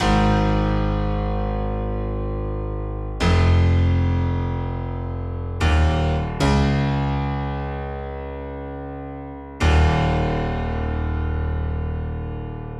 宇宙 小提琴
描述：与钢琴搭配
标签： 150 bpm Trap Loops Violin Loops 2.15 MB wav Key : Unknown
声道立体声